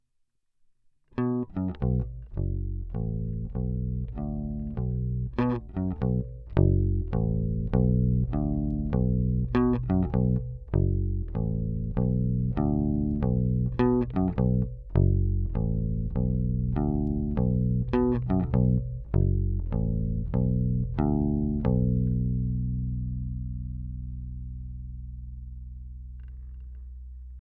Snímač by měl být DiMarzio DP122 + přepínač serie/paralel
trsátko série